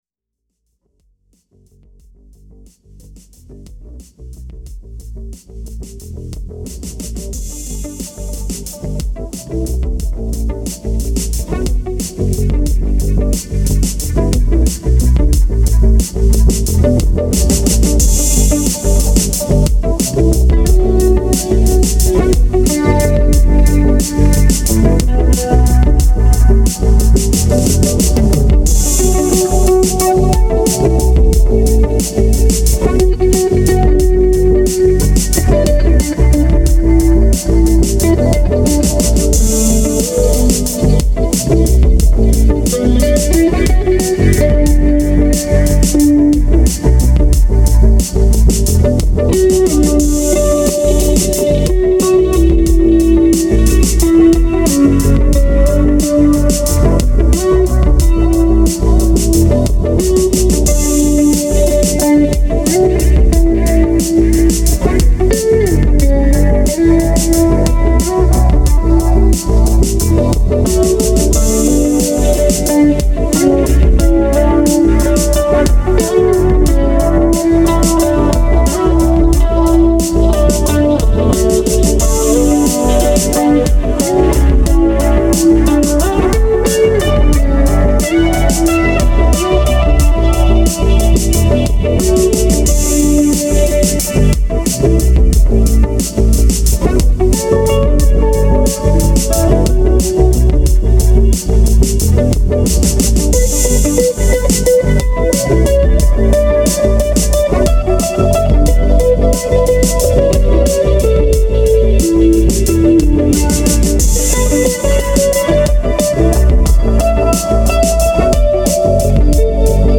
Synths n guitars
that would make a great soundtrack for an Alien science fiction type movie or show!